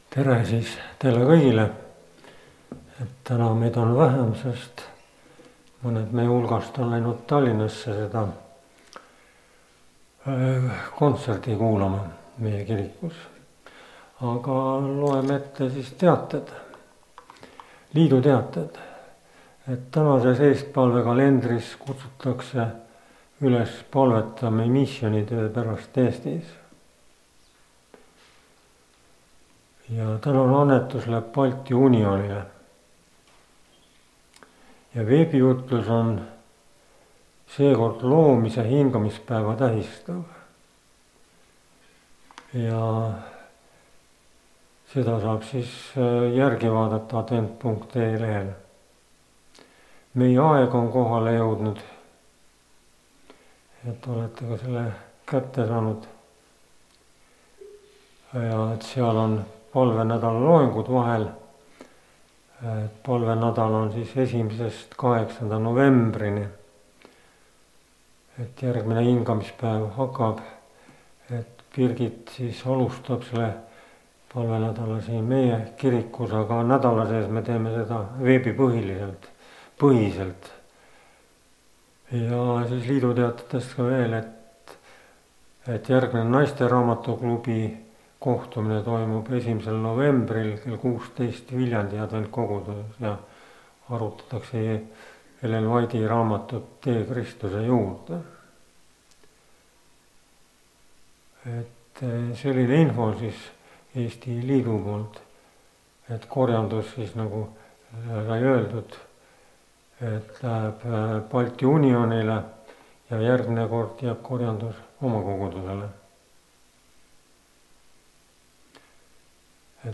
kategooria Audio / Koosolekute helisalvestused